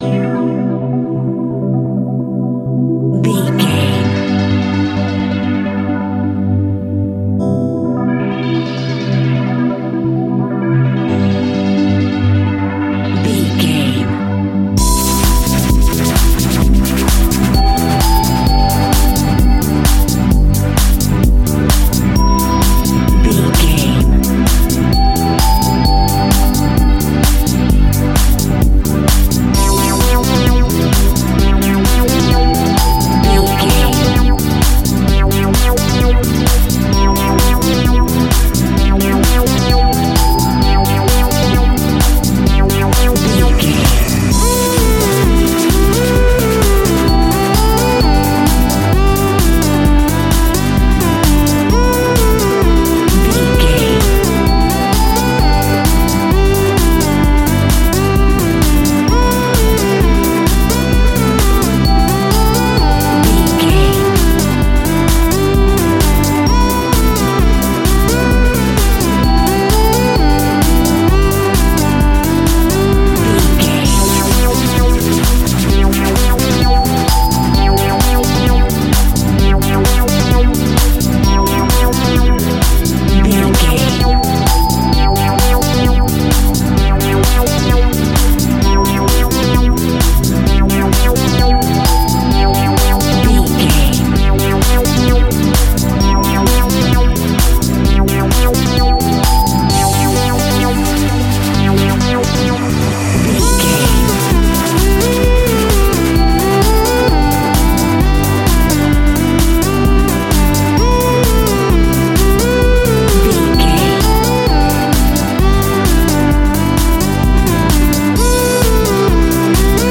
Ionian/Major
groovy
hypnotic
uplifting
drum machine
funky house
nu disco
upbeat
funky guitar
wah clavinet
synth bass
horns